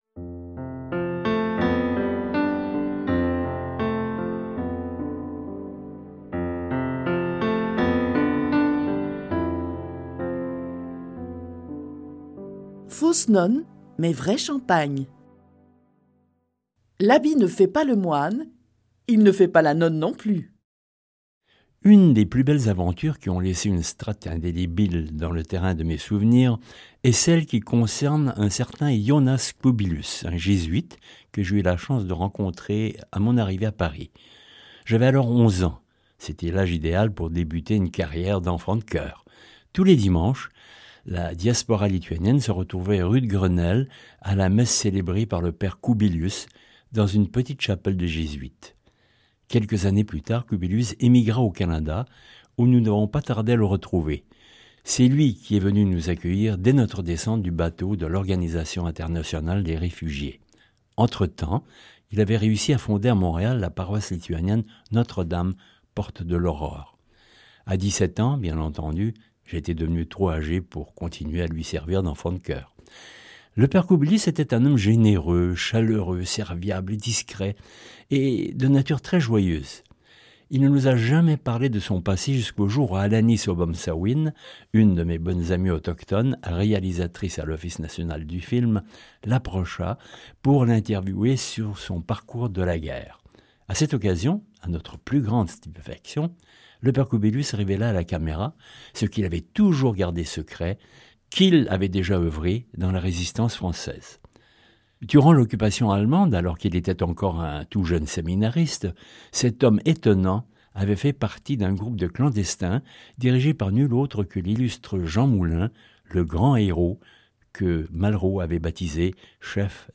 Click for an excerpt - Belles histoires d'une sale guerre de Alain Stanké